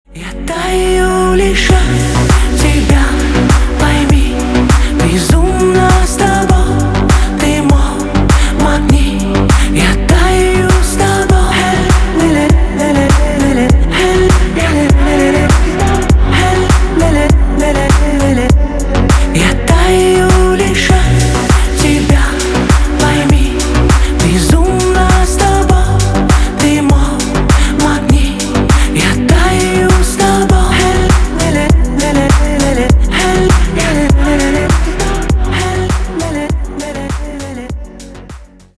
чувственные